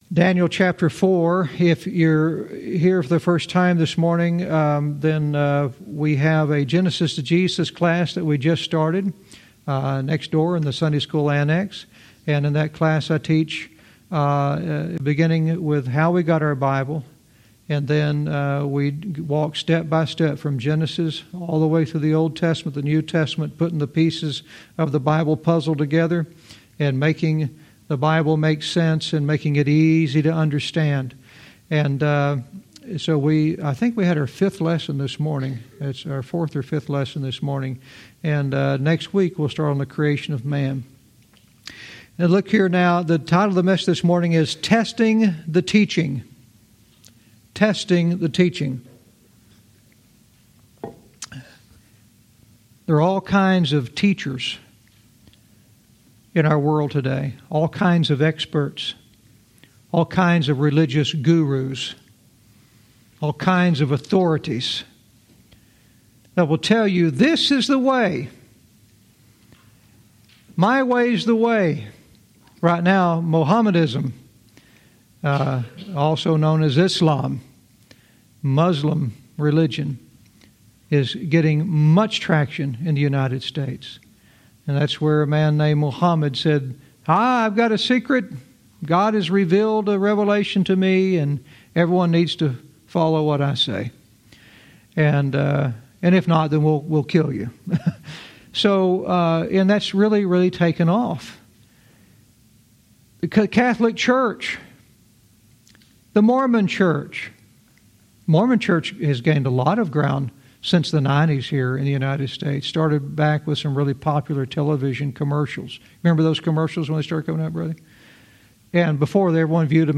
Verse by verse teaching - Daniel 4:6-9 "Testing the Teaching"